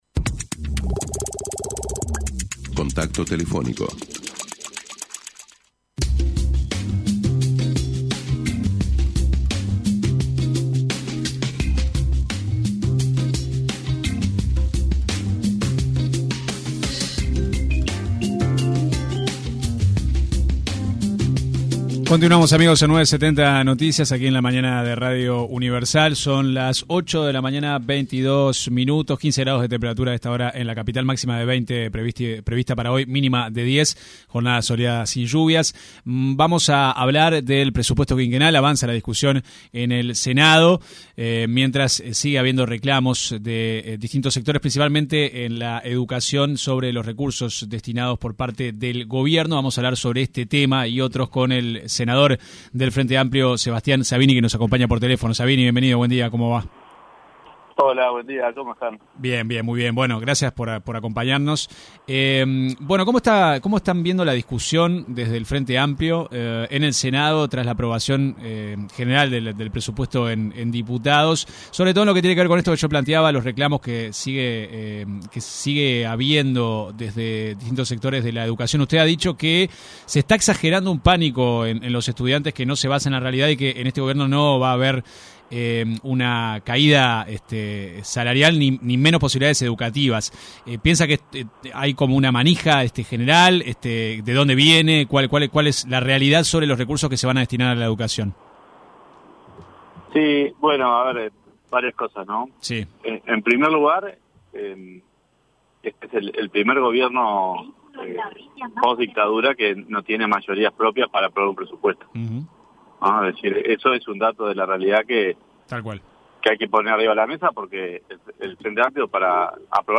El senador del Frente Amplio, Sebastián Sabini, se refirió en diálogo con 970 Noticias, al presupuesto destinado a la educación.